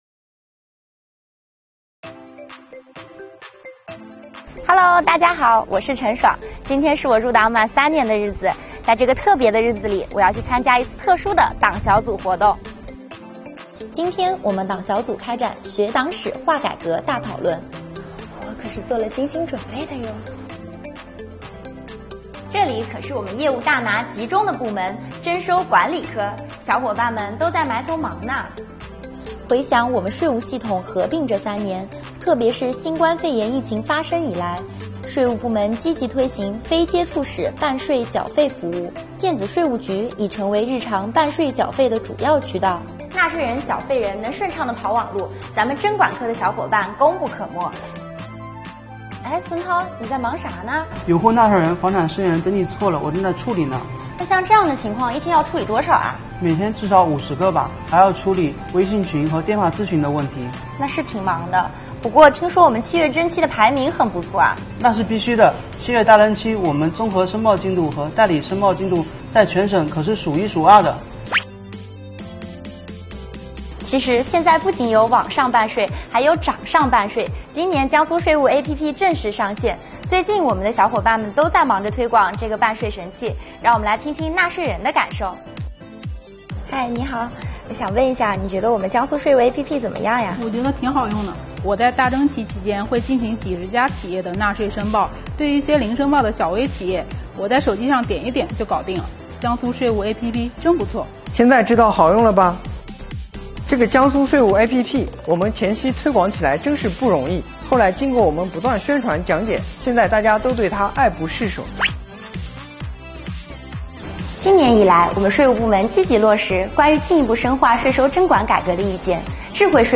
全片风格清新、活泼，制作精良，运动镜头、特写镜头和固定镜头相互搭配，配合轻松富有活力的音乐，引发读者共鸣，引起读者观看兴趣。